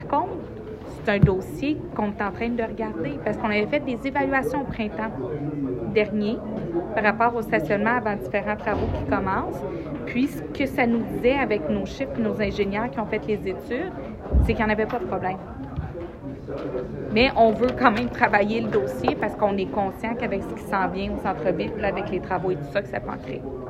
À la séance du conseil municipal de Granby hier soir